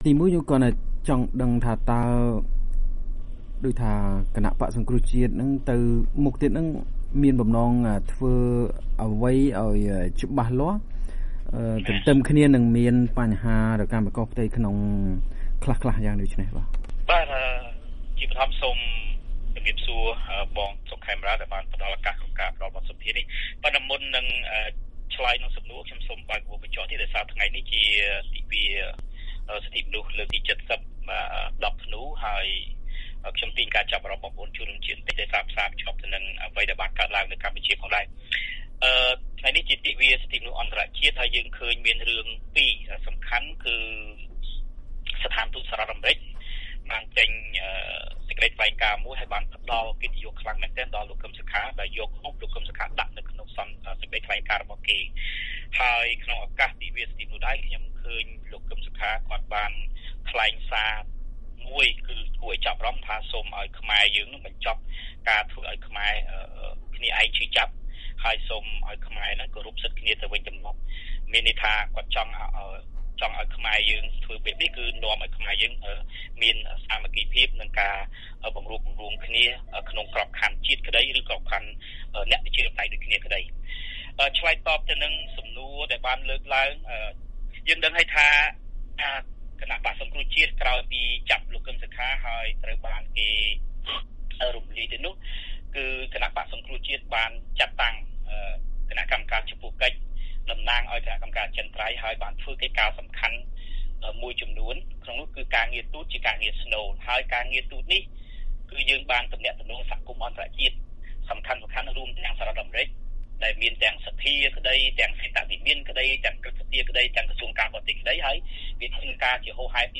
បទសម្ភាសន៍ VOA៖ មន្ត្រីសង្គ្រោះជាតិរំពឹងថា អ្នកប្រជាធិបតេយ្យនឹងមានការរួបរួមគ្នា ទោះជារកាំរកូសផ្ទៃក្នុងខ្លះក្តី